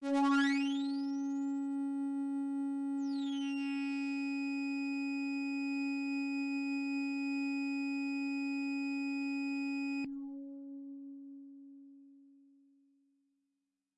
描述：通过Modular Sample从模拟合成器采样的单音。